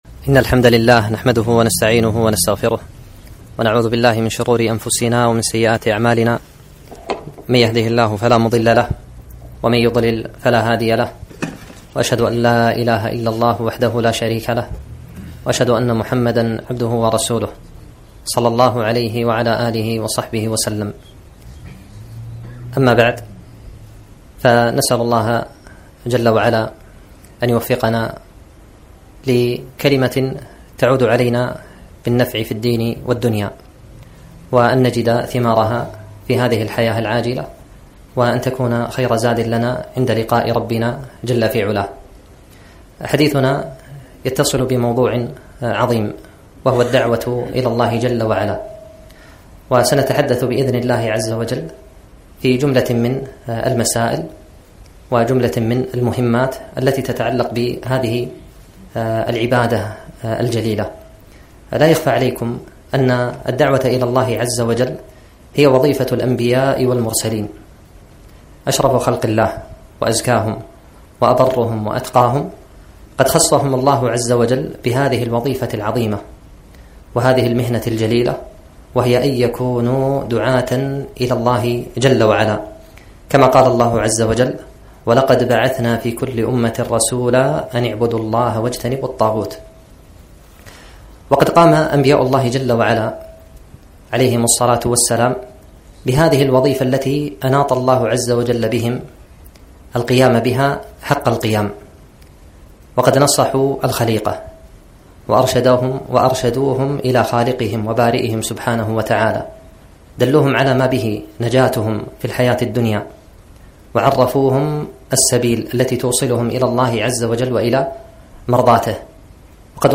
محاضرة - مهمات في الدعوة إلى الله